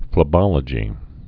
(flĭ-bŏlə-jē)